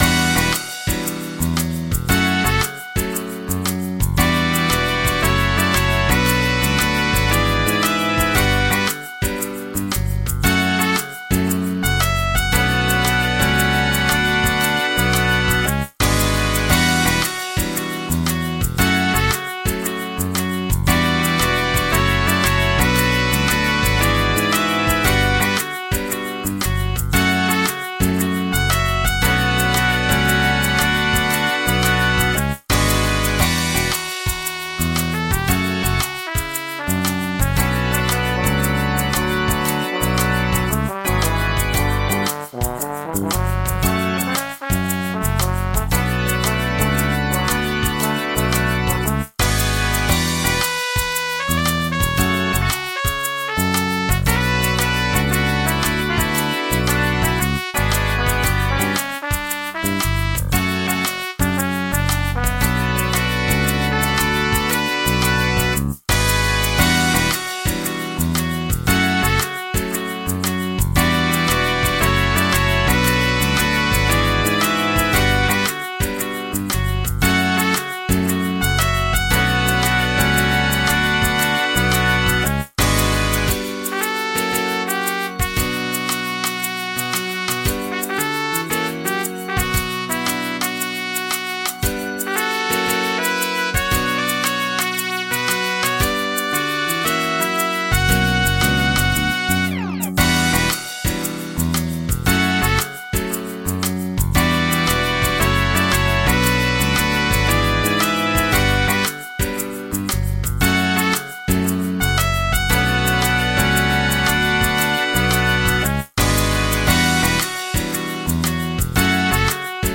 MIDI 36.57 KB MP3 (Converted) 3.09 MB MIDI-XML Sheet Music